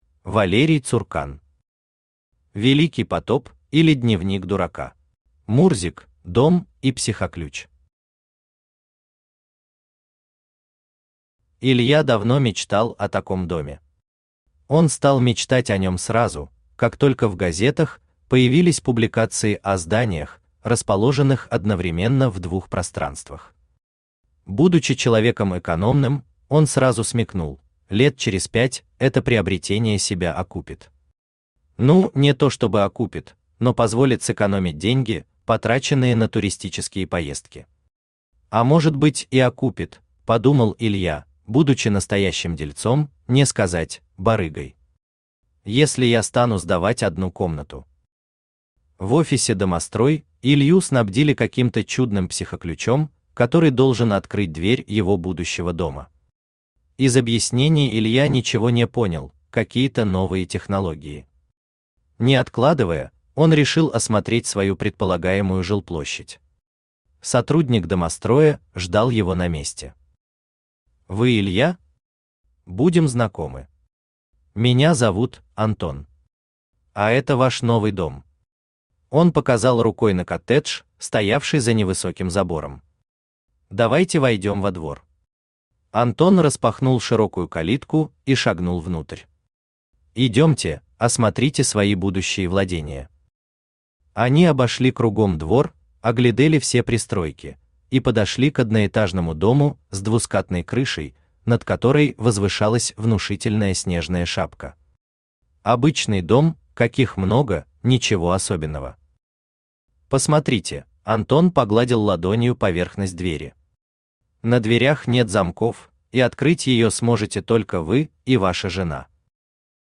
Aудиокнига Великий потоп, или Дневник дурака Автор Валерий Цуркан Читает аудиокнигу Авточтец ЛитРес.